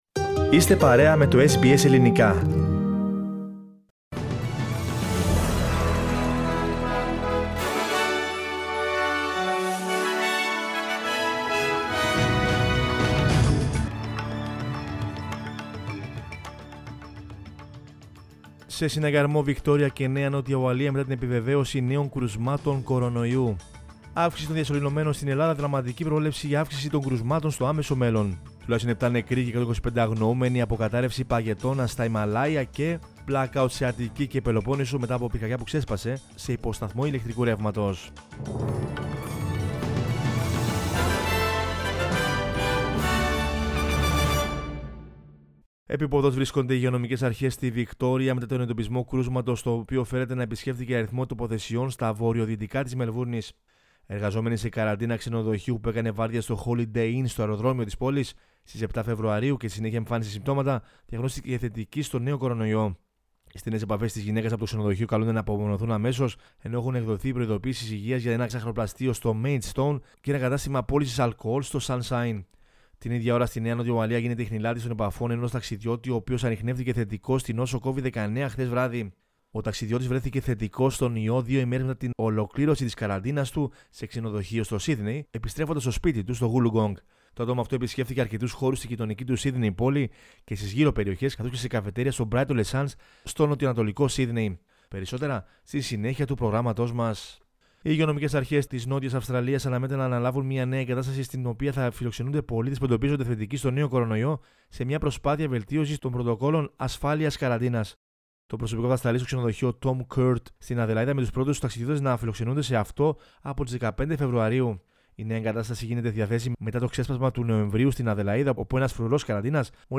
News in Greek from Australia, Greece, Cyprus and the world is the news bulletin of Monday 8 February 2021.